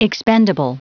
Prononciation du mot expendable en anglais (fichier audio)
Prononciation du mot : expendable